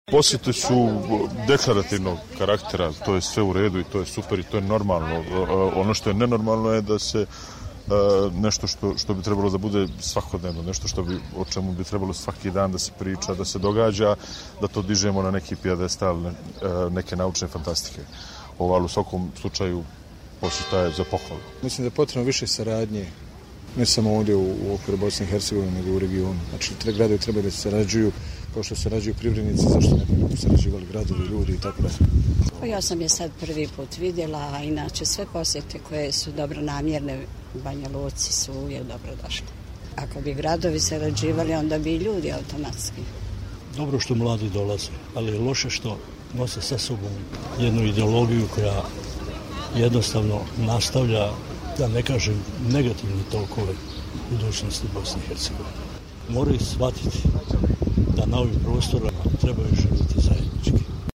Građani Banjaluke o susretu gradonačelnika